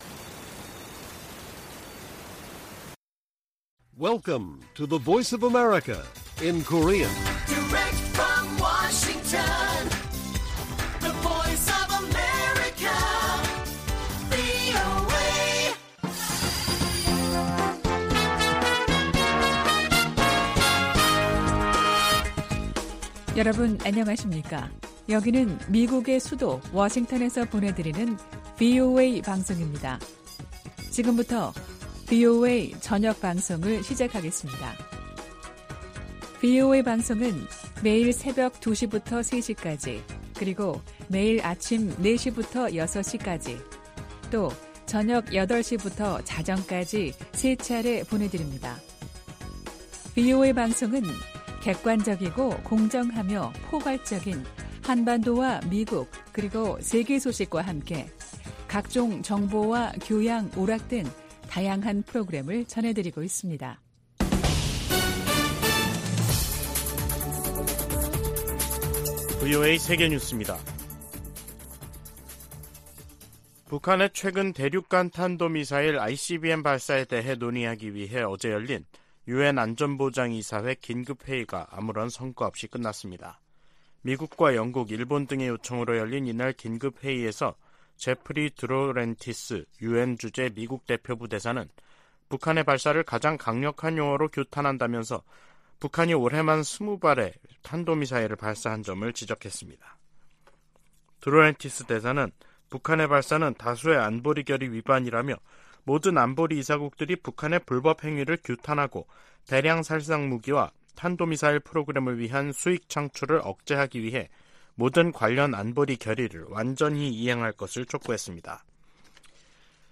VOA 한국어 간판 뉴스 프로그램 '뉴스 투데이', 2023년 7월 14일 1부 방송입니다. 미한일과 유럽 나라들이 북한의 대륙간탄도미사일(ICBM) 발사 문제를 논의한 유엔 안보리 회의에서 북한을 강력 규탄하며 안보리의 단합된 대응을 거듭 촉구했습니다. 김정은 북한 국무위원장이 할 수 있는 최선의 방안은 대화 복귀라고 미 백악관이 강조했습니다. 한국 정부가 북한의 ICBM 발사에 대응해 정경택 인민군 총정치국장 등 개인 4명과 기관 3곳을 제재했습니다.